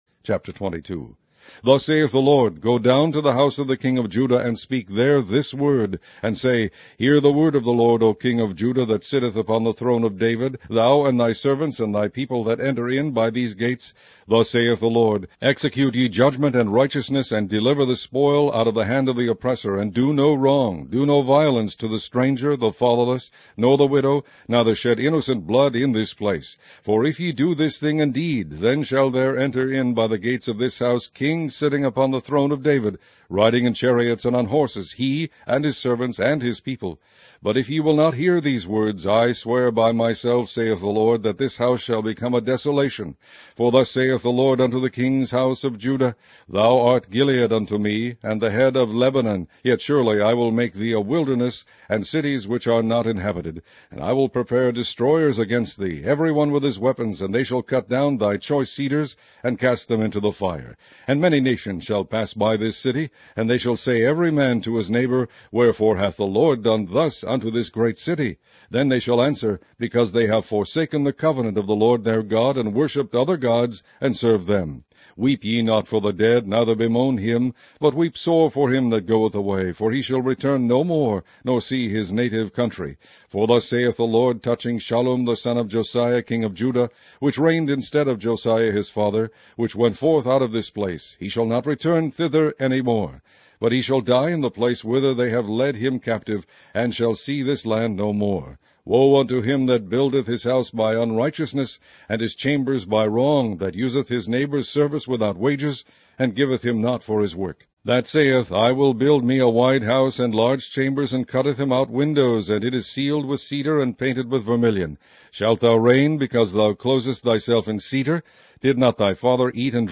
Online Audio Bible - King James Version - Jeremiah